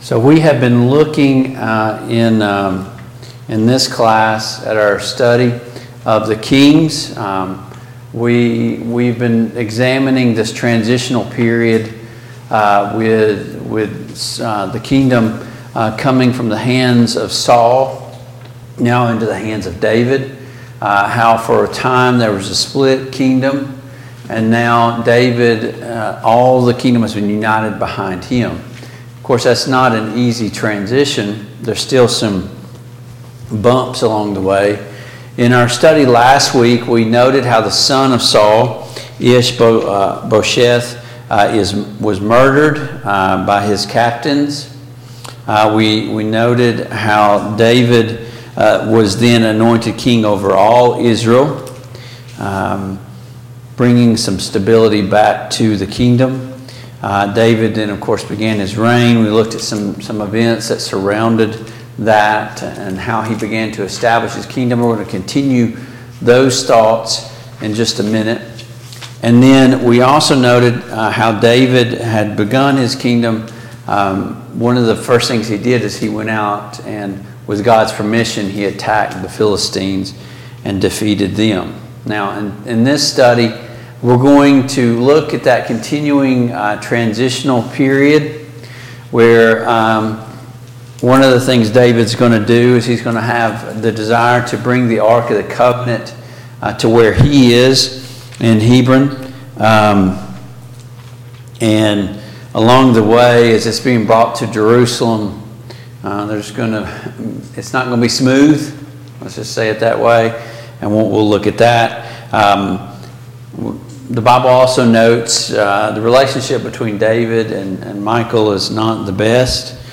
The Kings of Israel Passage: II Samuel 6, Service Type: Mid-Week Bible Study Download Files Notes « Are we born sinful?